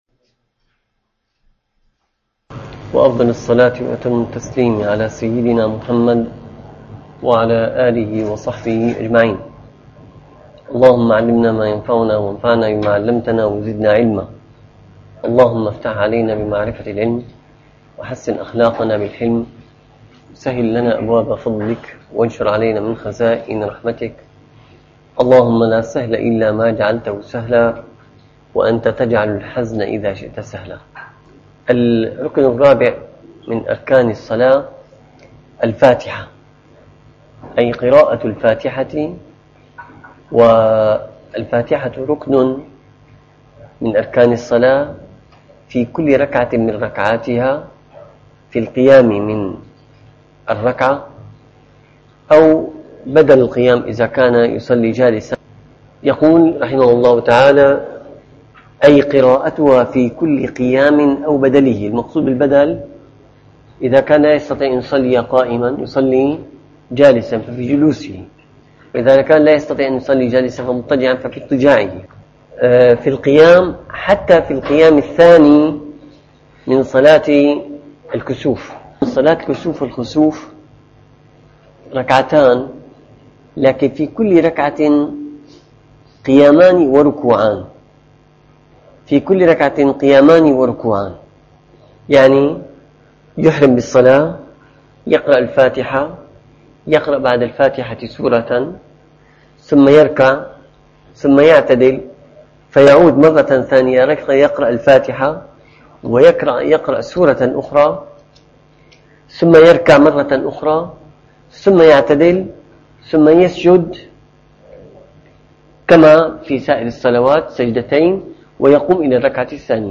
- الدروس العلمية - الفقه الشافعي - المنهاج القويم شرح المقدمة الحضرمية - تكملة لصفة الصلاة.